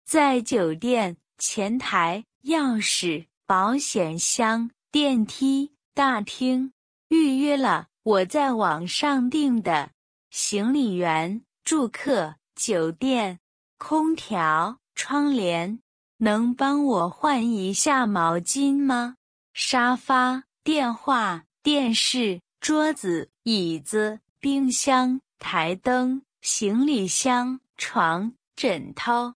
中国語読み
声：音読さん